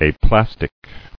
[a·plas·tic]